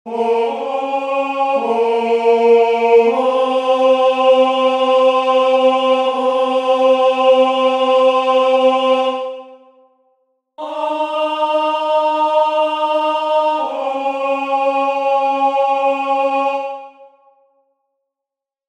Key written in: A♭ Major
Type: Barbershop
Each recording below is single part only.